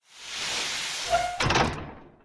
scpcb-godot/SFX/Door/DoorOpen1.ogg at d1278b1e4f0e2b319130f81458b470fe56e70c55
DoorOpen1.ogg